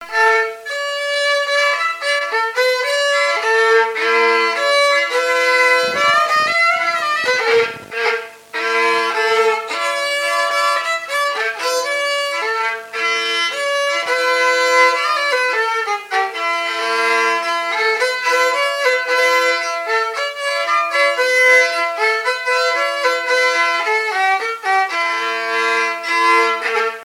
Localisation Montreuil (Plus d'informations sur Wikipedia)
Fonction d'après l'analyste danse : quadrille : pastourelle ;
Catégorie Pièce musicale inédite